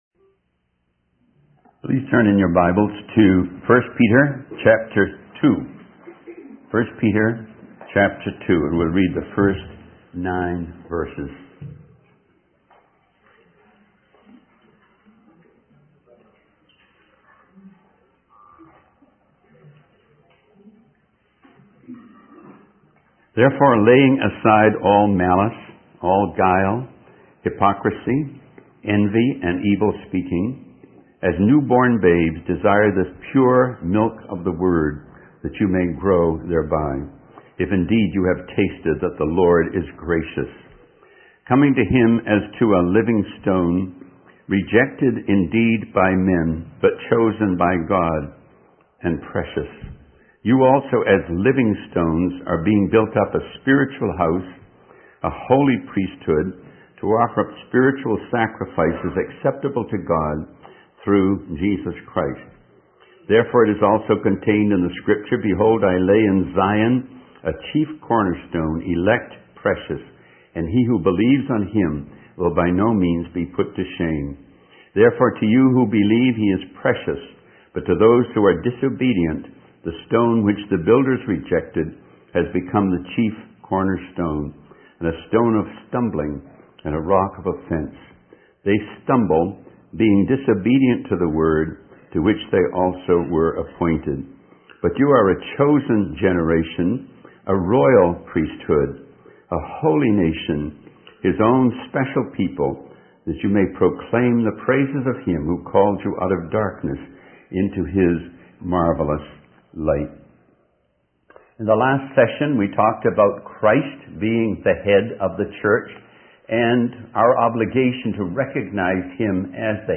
In this sermon, the speaker discusses the challenges and temptations faced by preachers in today's world. He emphasizes the importance of staying true to the message of the gospel and not compromising spiritual standards in order to attract more people.